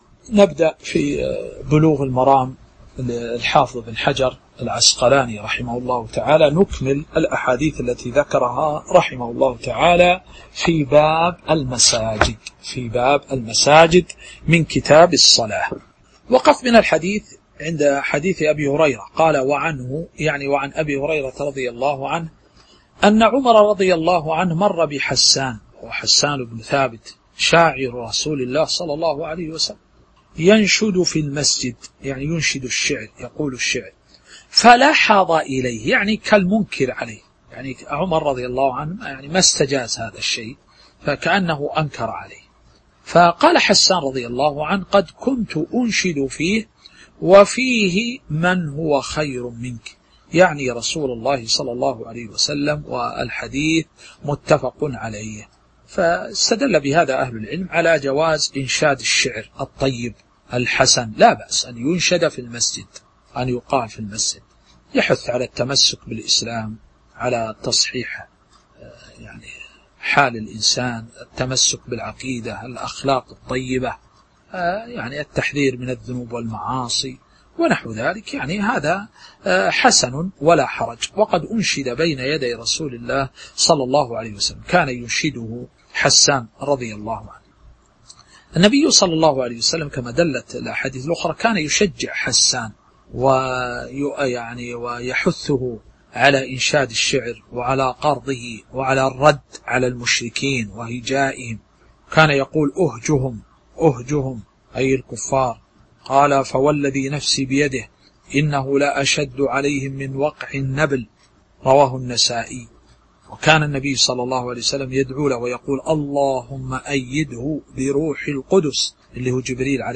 تاريخ النشر ٩ ربيع الأول ١٤٤٥ هـ المكان: المسجد النبوي الشيخ